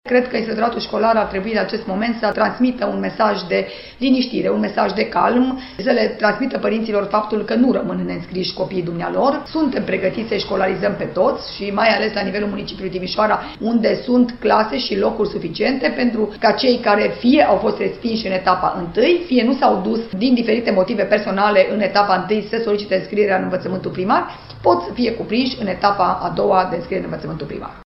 Inspectoratul Şcolar Judeţean Timiş dă asigurări părinţilor că cei mici vor avea locuri suficiente pe care să fie distribuiţi şi în a doua etapă. Inspector General, Aura Danielescu, transmite un mesaj de calm părinților.